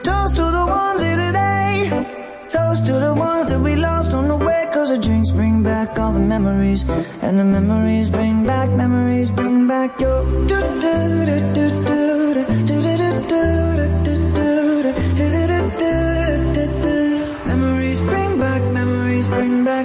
American pop rock band
Pop Band